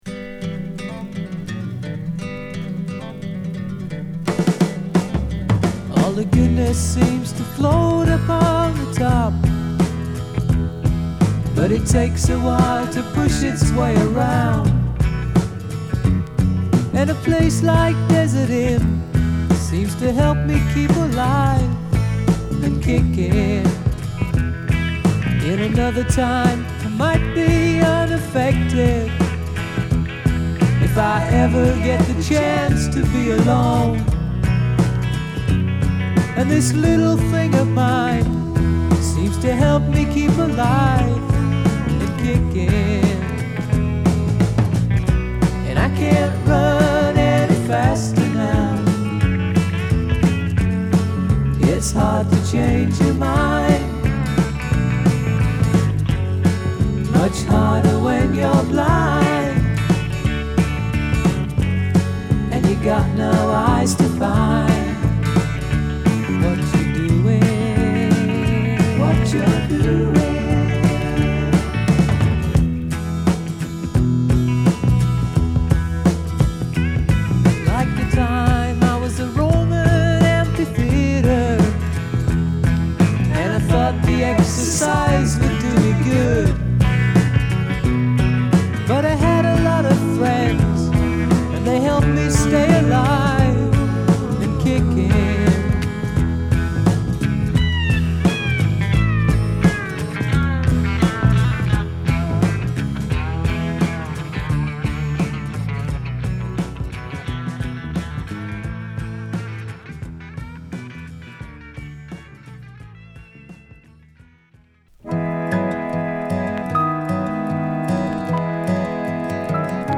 独特の湿りがあるグルーヴィーなSSW作品。